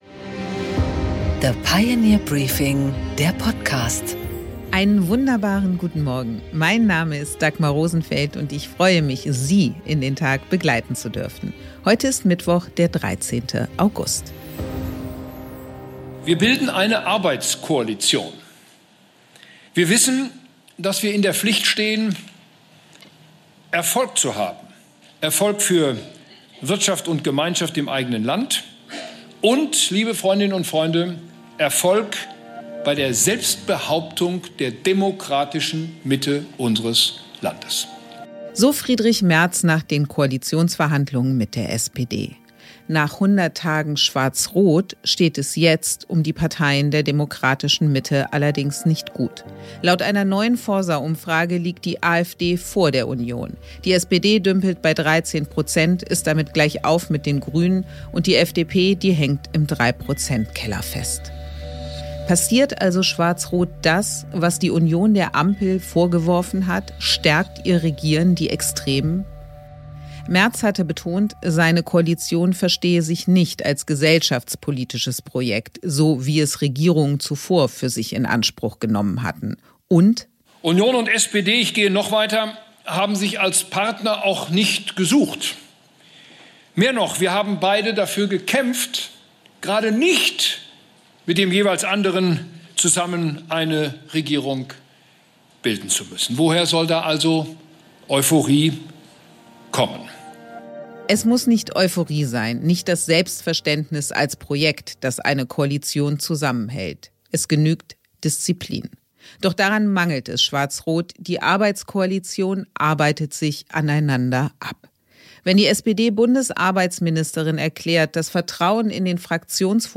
Im Gespräch
Interview mit Sigmar Gabriel